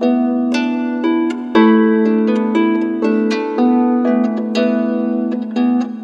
Harp11_119_G.wav